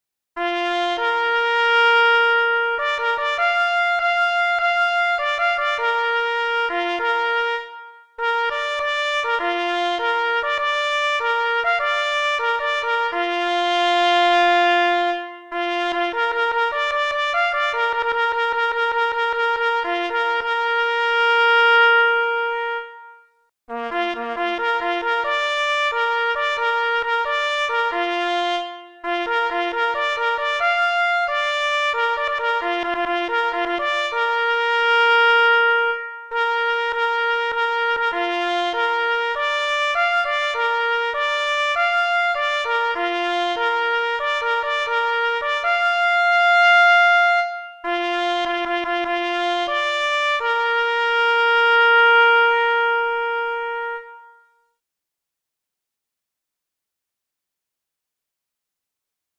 ples solo